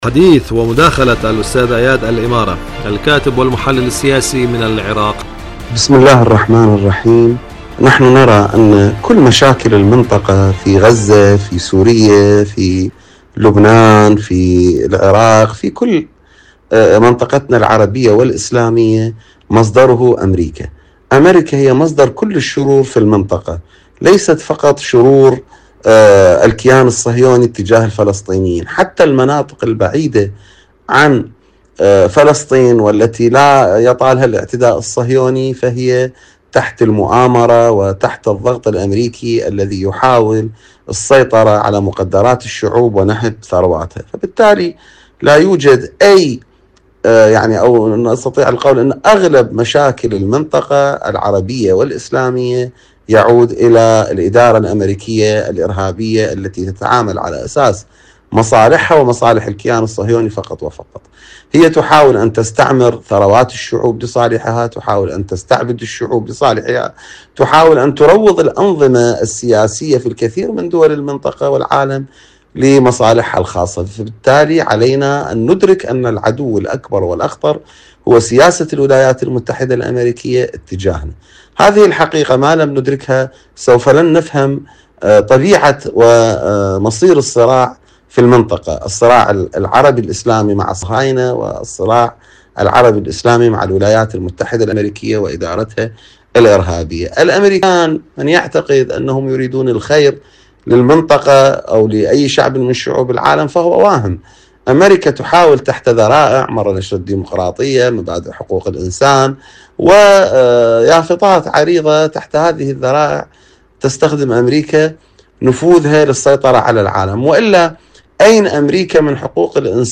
إذاعة طهران- حدث وحوار: مقابلة إذاعية